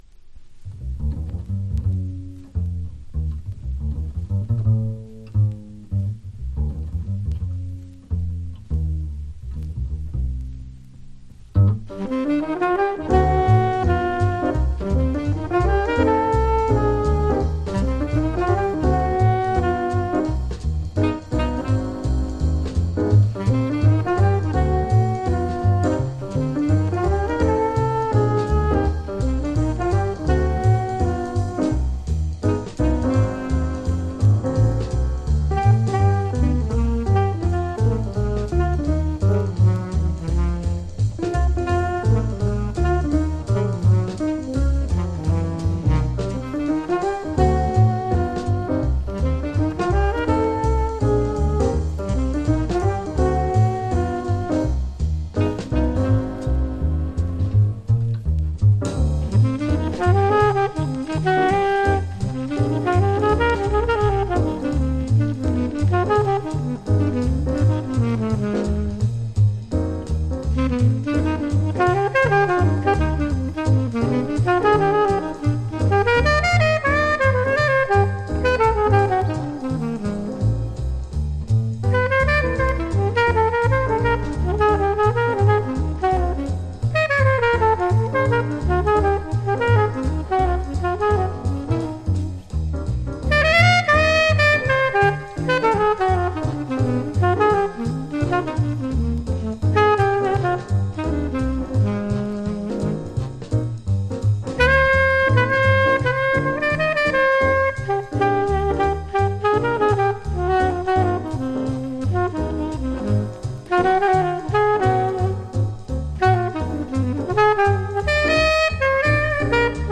4曲トリオ。“